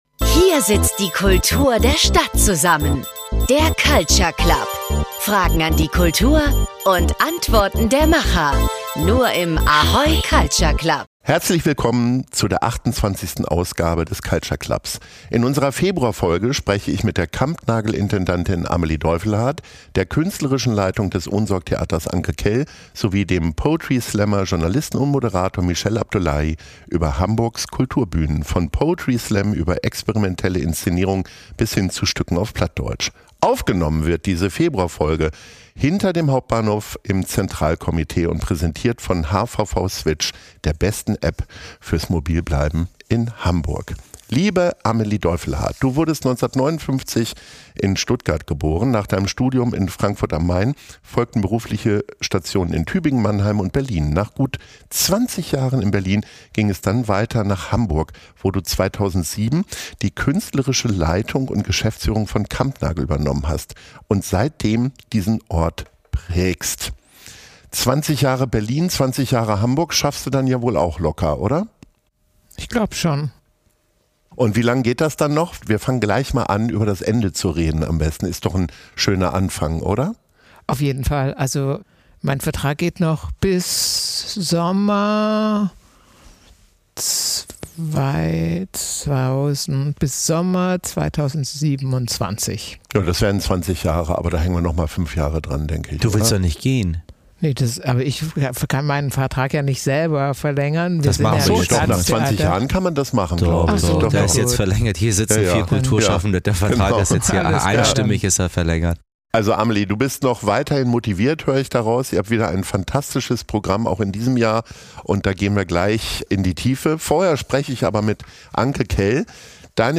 Aufgenommen wurde diese Februar-Folge hinter dem Hauptbahnhof im Centralkomitee und wird präsentiert von hvv Switch, der besten App fürs mobil bleiben in Hamburg.